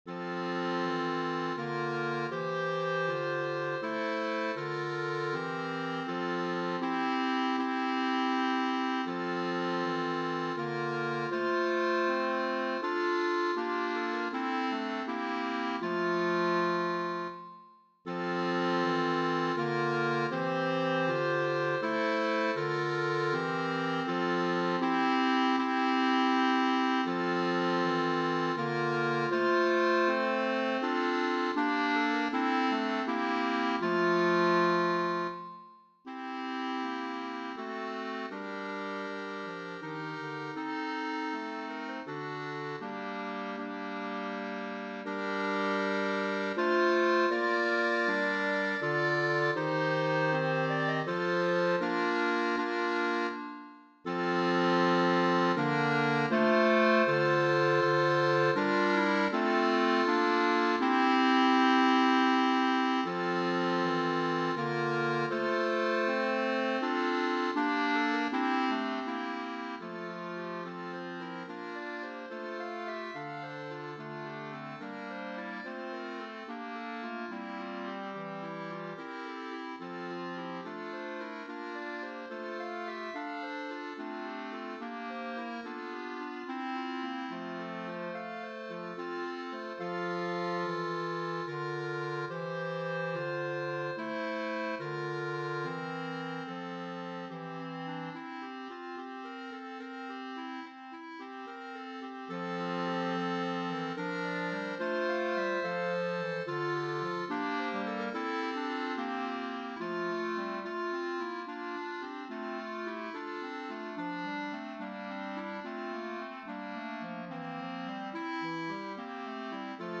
Voicing: 4 Clarinet